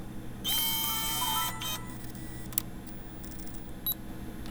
And here's the spectral analysis inside CoolEdit Pro of the sounds made with Canon PowerShot S50 digital photo camera, as recorded by Delta and PCM-M1. As you can see it clearly records sounds 20,000 Hz and above.
By the way the background noise you hear in the above WAVE sample is my computer, which is quite loud.
pcmm1anddelta.wav